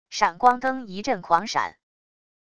闪光灯一阵狂闪wav音频